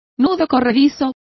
Complete with pronunciation of the translation of noose.